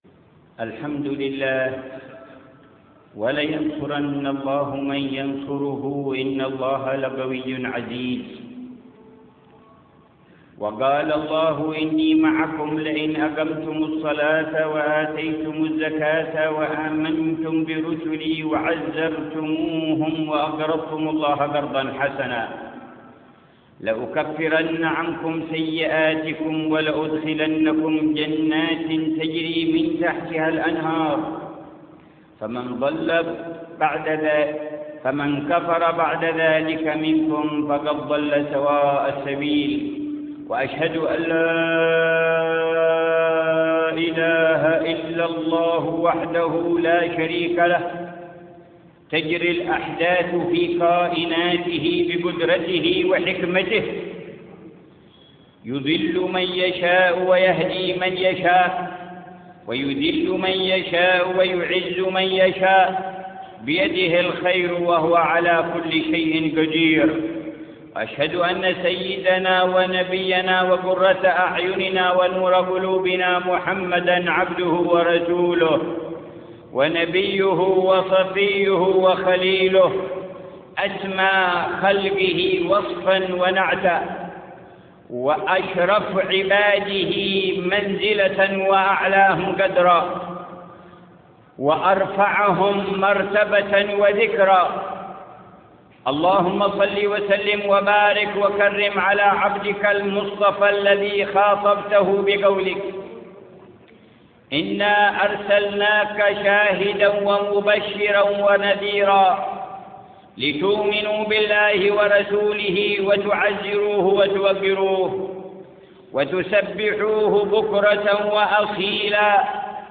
خطبة جمعة
بمدينة المكلا - حضرموت